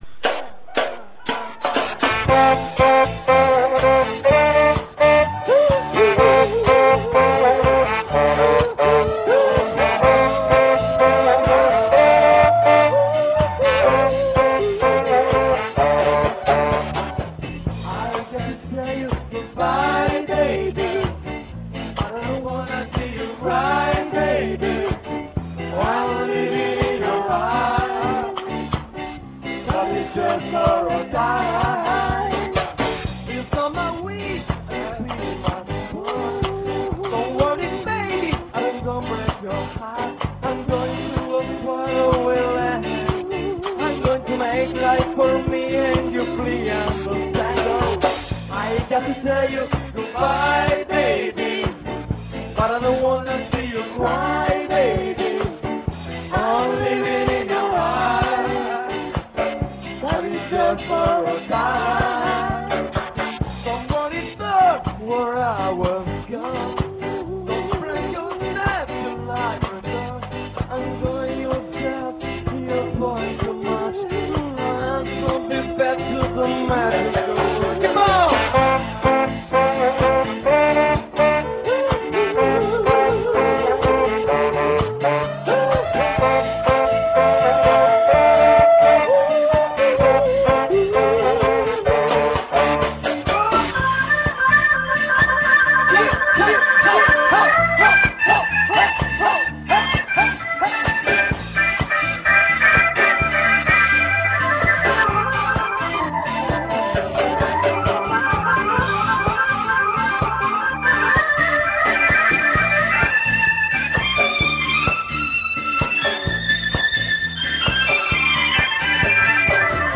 registrata al concerto